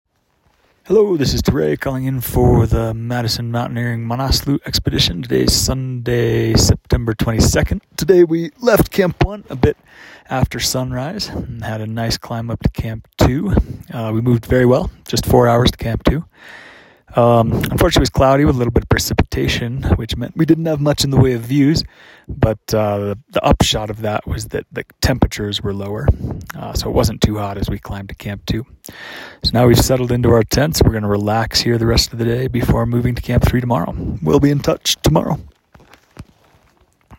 The Madison Mountaineering Manaslu expedition team has reached Camp 2 and settled in for the night! The team moved swiftly between camps, making good time with cool temperatures as they climbed to around 6,400 m/21,000 ft.